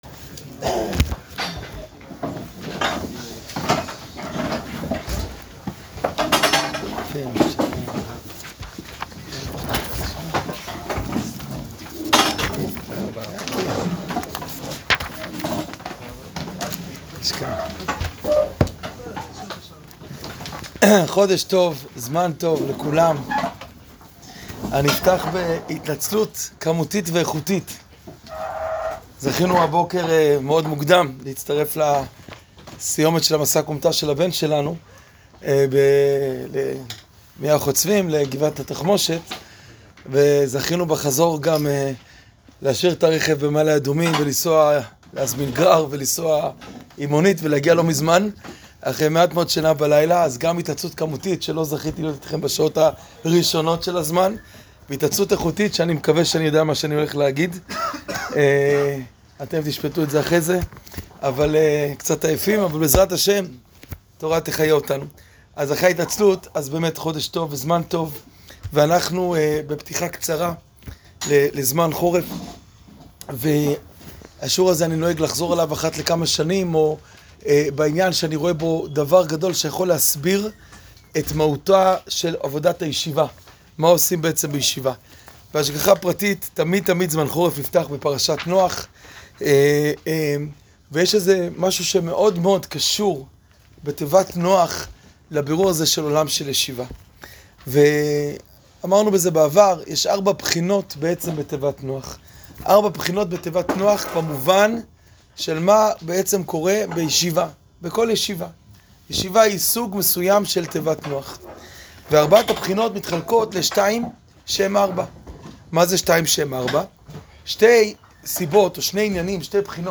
שיעור פתיחה לזמן חורף